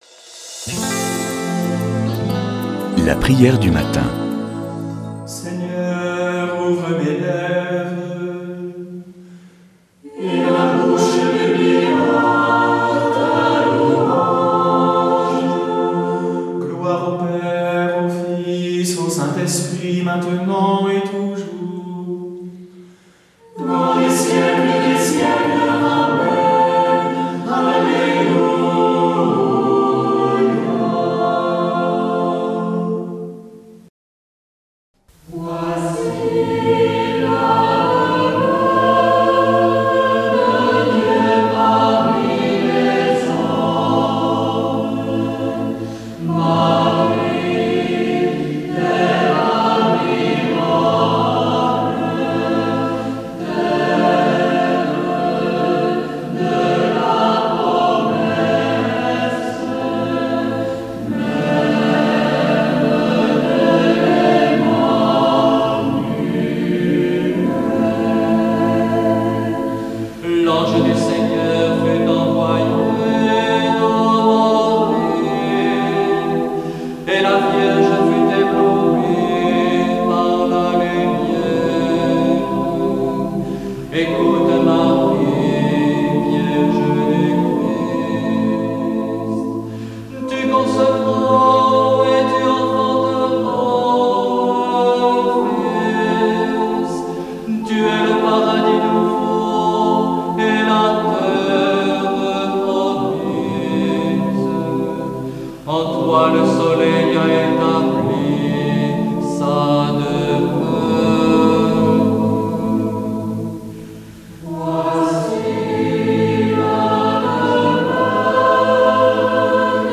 Prière du matin